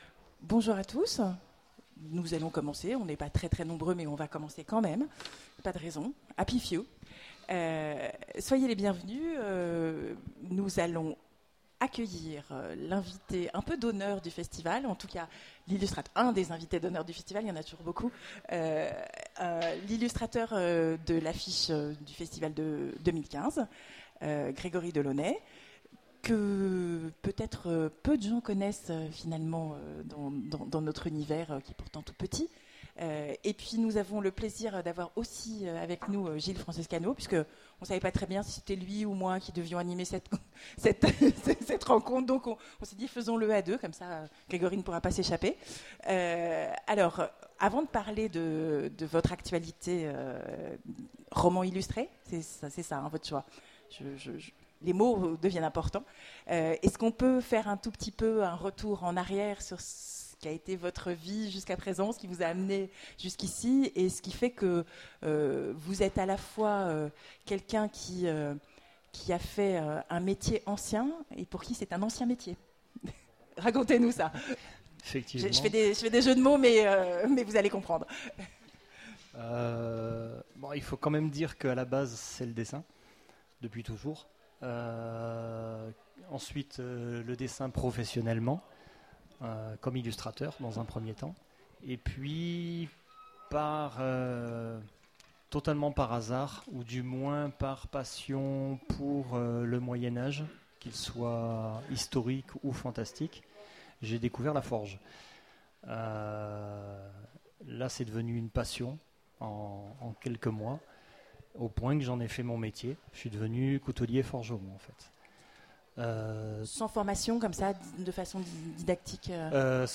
Imaginales 2015
Mots-clés Rencontre avec un auteur Conférence Partager cet article